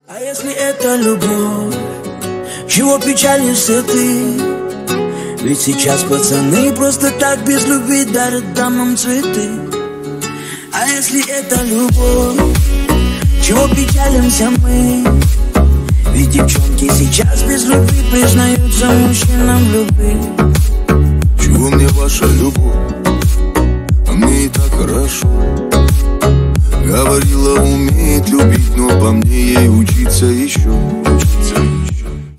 Ремикс # Рэп и Хип Хоп
грустные